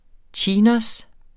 Udtale [ ˈtjiːnʌs ]